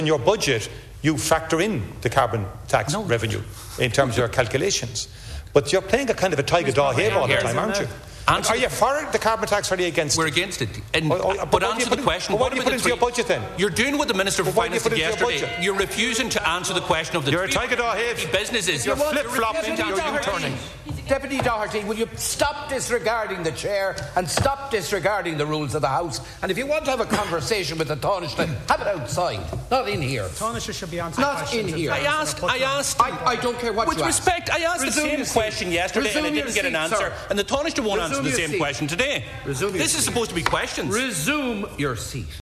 Donegal Deputy Pearse Doherty has been given a dressing down in the Dail again.
This time the Ceann Comhairle was forced to take to his feet: